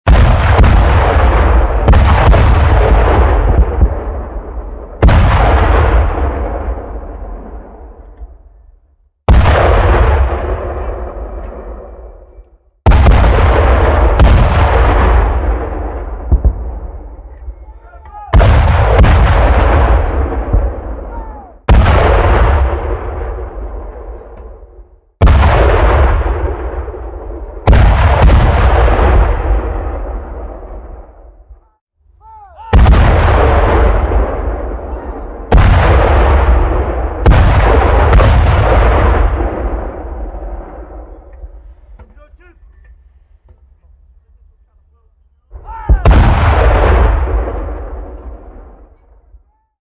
جلوه های صوتی
دانلود صدای تانک 3 از ساعد نیوز با لینک مستقیم و کیفیت بالا